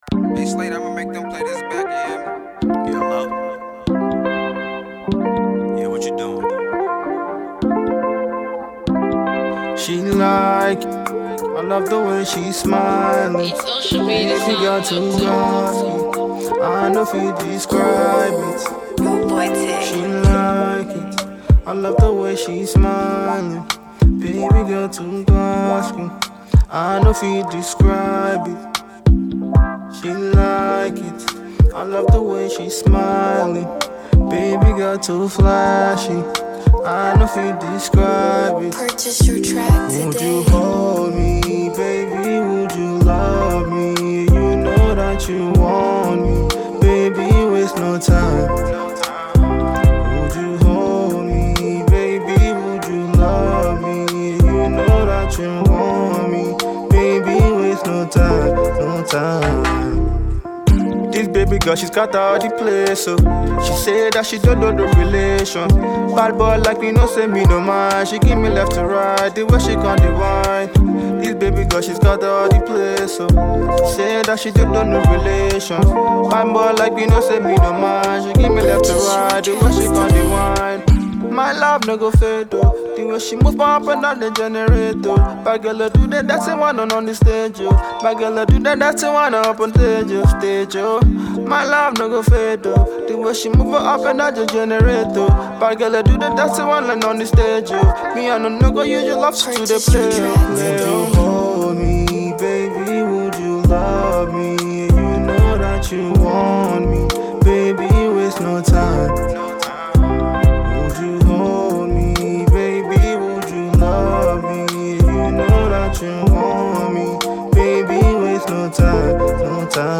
A sweet sensation jam that will leave you asking for more